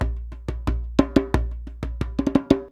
089DJEMB12.wav